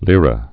(lîrə, lērä)